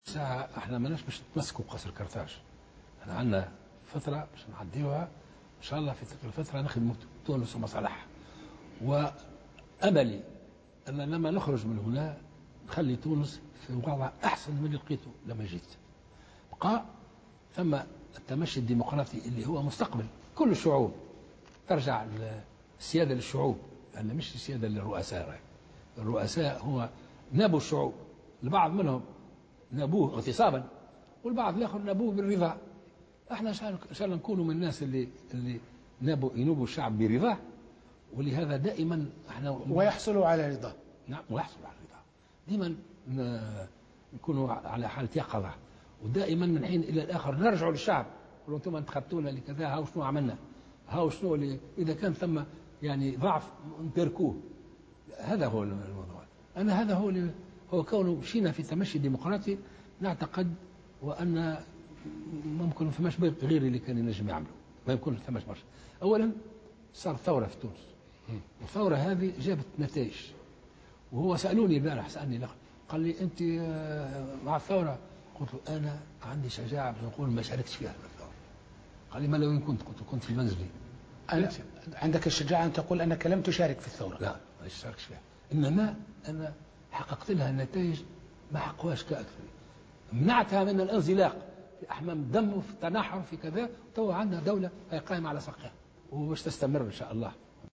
قال رئيس الجمهورية الباجي قائد السبسي في حوار أدلى به مساء اليوم لقناة "العربية" إنه ليس متمسكا بقصر قرطاج وإنه سيعمل طيلة فترة توليه للرئاسة على خدمة تونس ومصالحها.